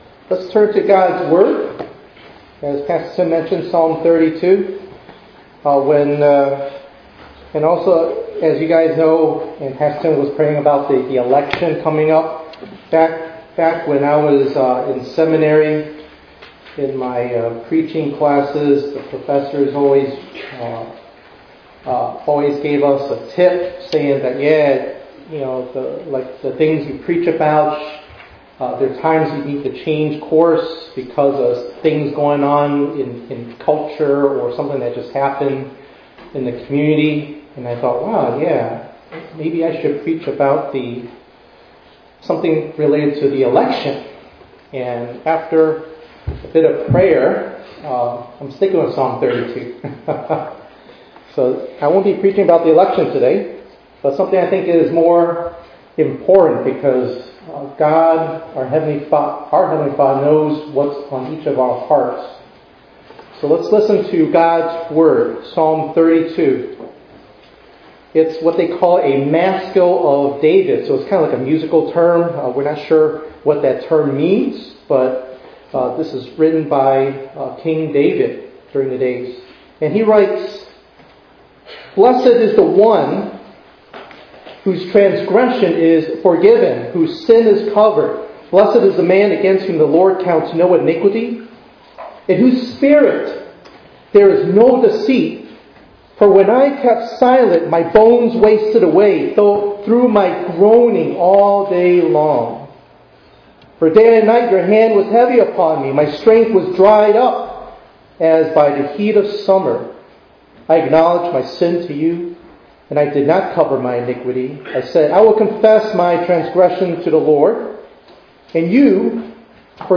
11_3_24_ENG_Sermon.mp3